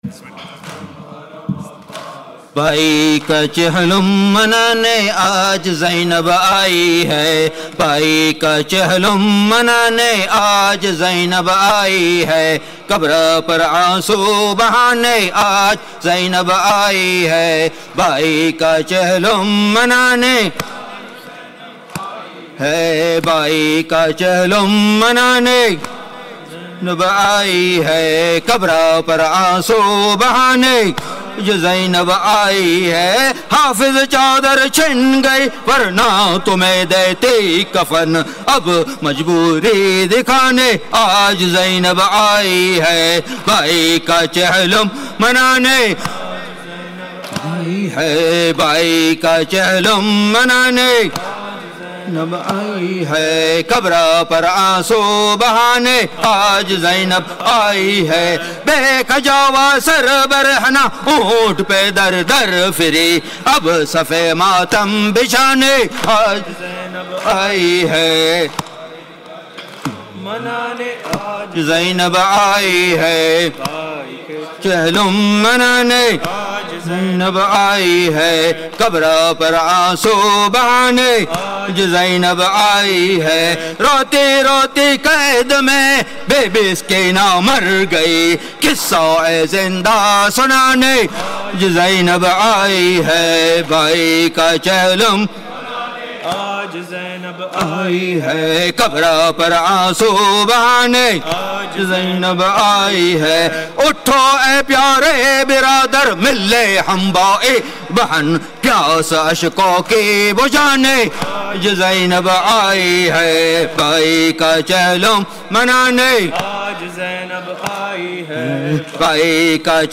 Chehlum / Arbaeen